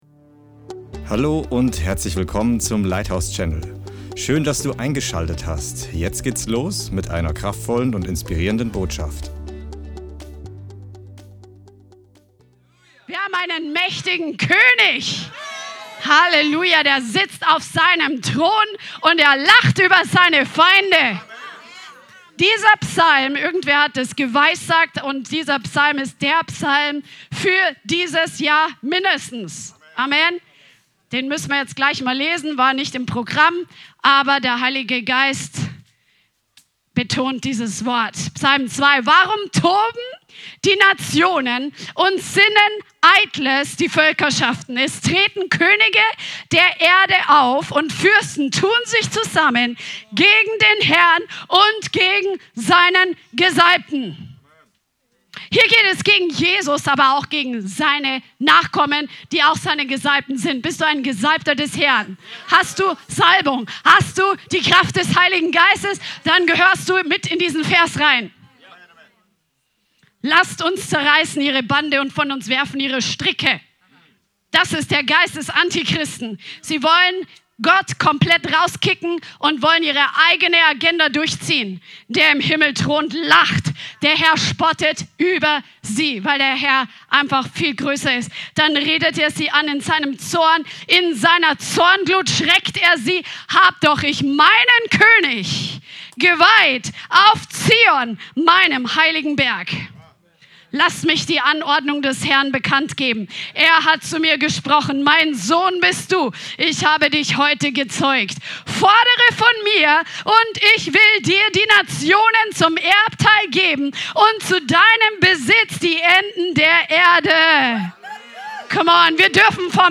Beschreibung vor 1 Jahr Trainingsabend 18.02.25